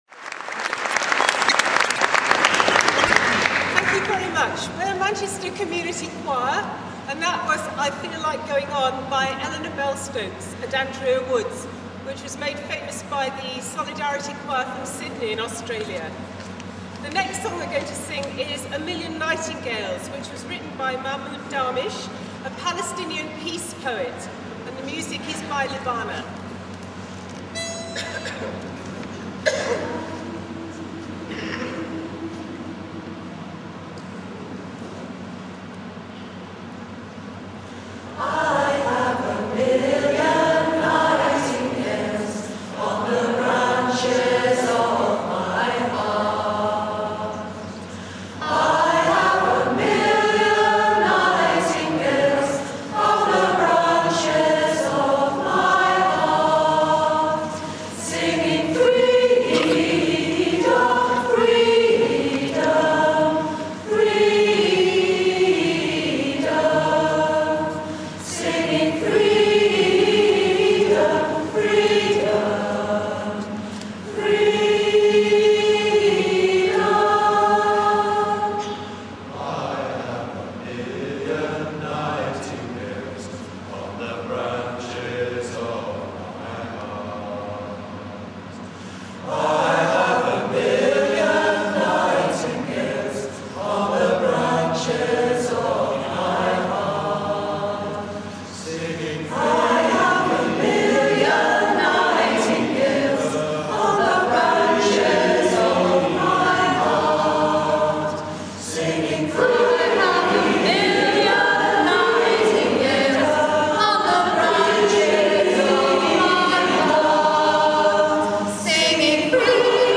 The wonderful Manchester Community Choir singing two songs at the huge Manchester Against Racism rally on 15th January in Manchester, Town Hall, UK.
The biggest room in Manchester Town Hall was filled to capacity on 15th January 2004 at the launch of Manchester Against Racism--a new initiative which is currently being repeated all over Britain.
Here is the second song, "A Million Nightingales" sung by Manchester Community Choir.
manchester_community_choir_a_million_nightingales3.mp3